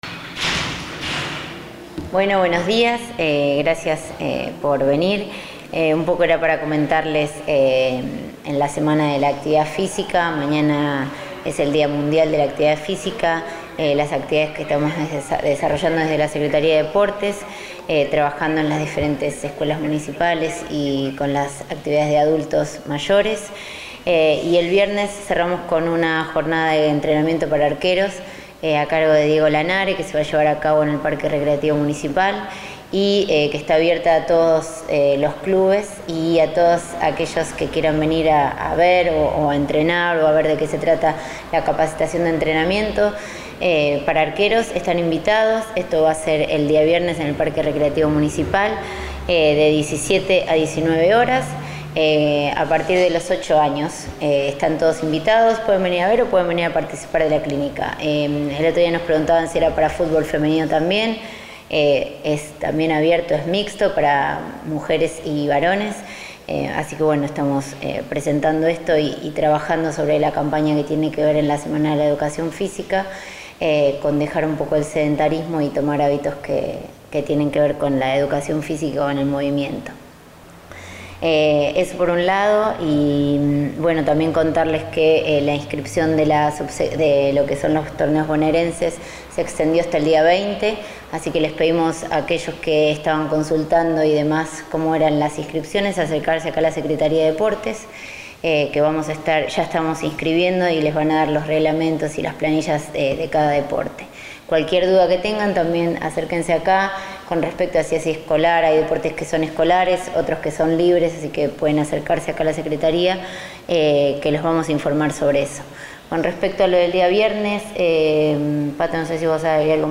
Se adjunta audio conferencia de prensa.
deportes-conferencia-de-prensa.mp3